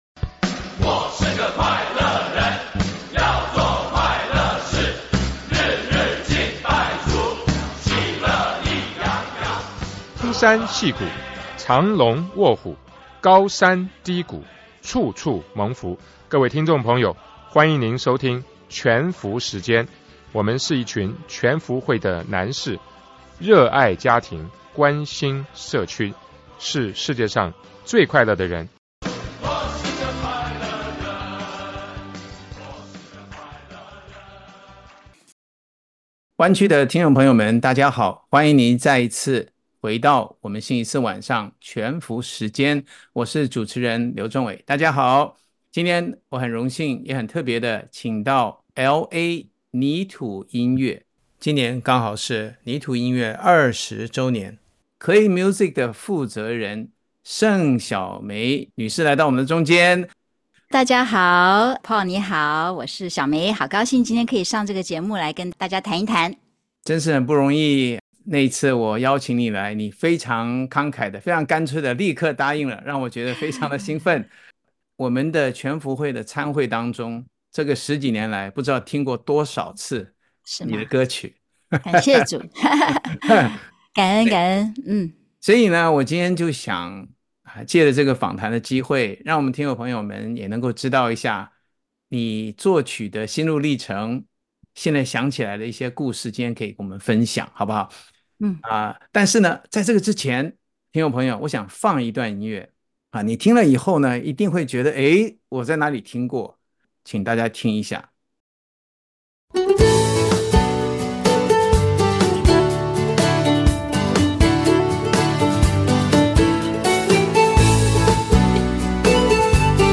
全福時間FM廣播節目剪輯